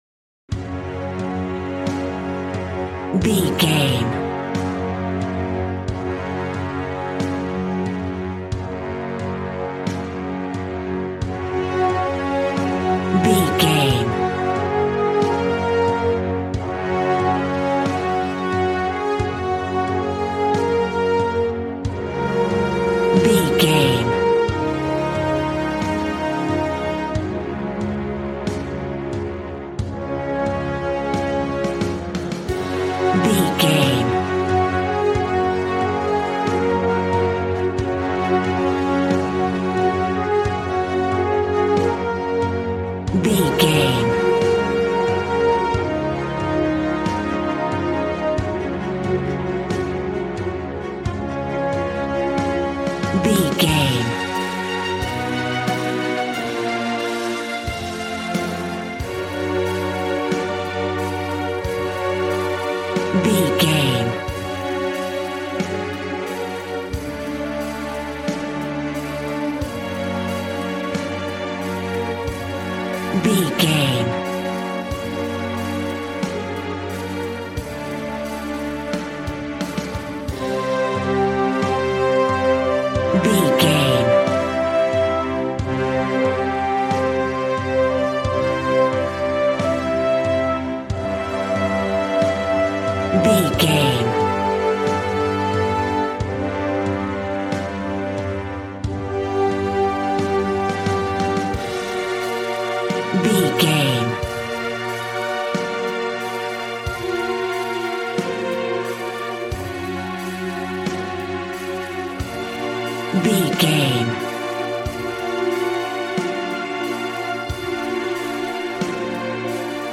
Ionian/Major
G♭
dramatic
strings
violin
brass